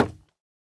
Minecraft Version Minecraft Version 1.21.5 Latest Release | Latest Snapshot 1.21.5 / assets / minecraft / sounds / block / bamboo_wood / break3.ogg Compare With Compare With Latest Release | Latest Snapshot
break3.ogg